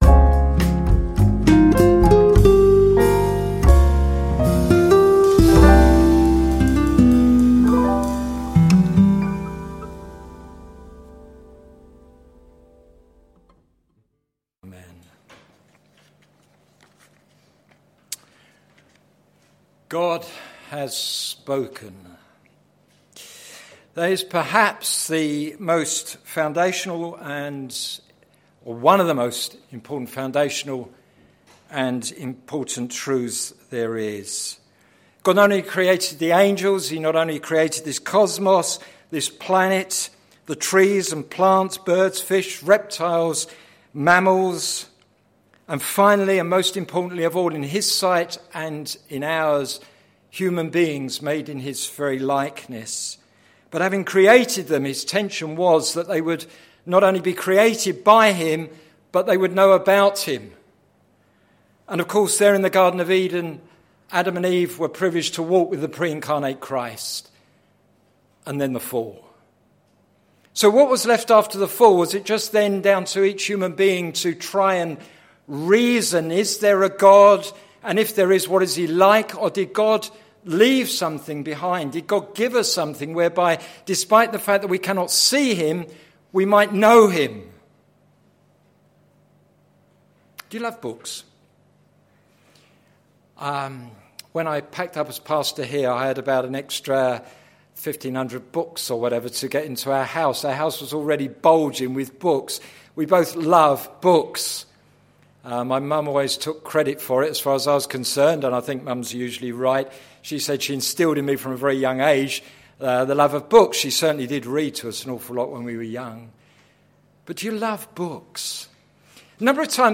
Sermon Series - Songs to live and songs to sing - plfc (Pound Lane Free Church, Isleham, Cambridgeshire)